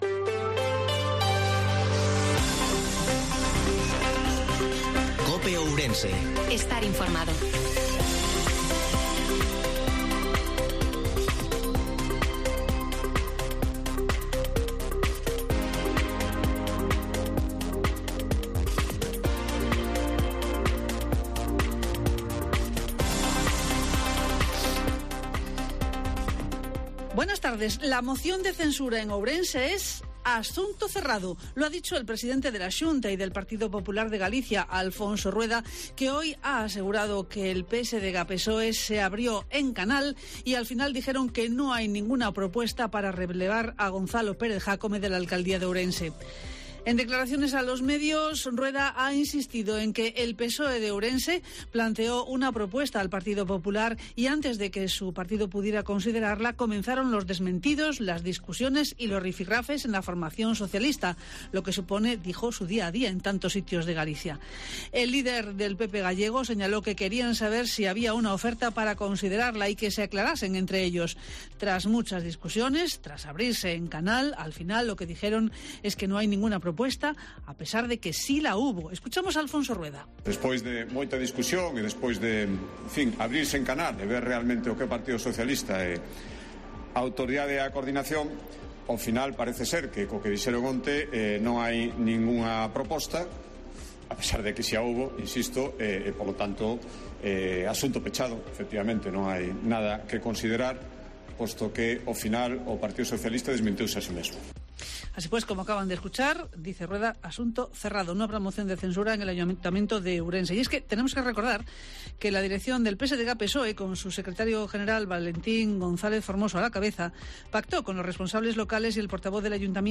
INFORMATIVO MEDIODIA COPE OURENSE-31/08/2022